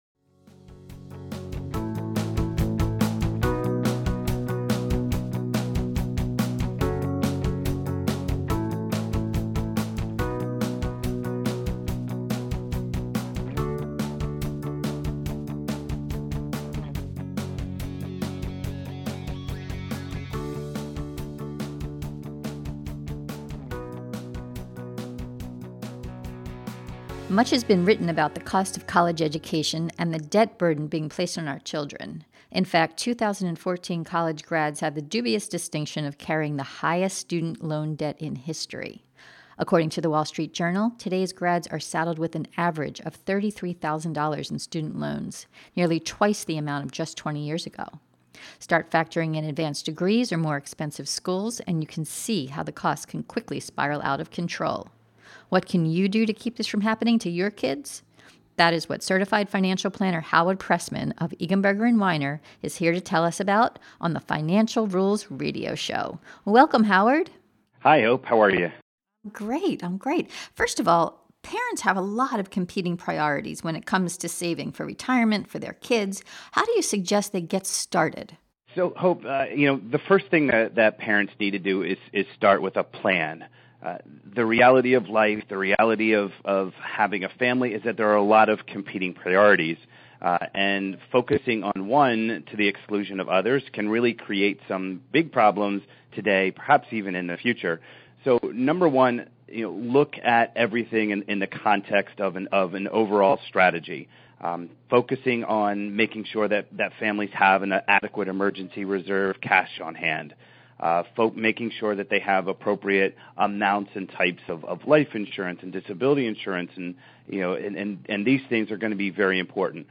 Finance Rules: EBW Financial News Radio Have You Saved Enough for Your Child's College Tuition?
In this interview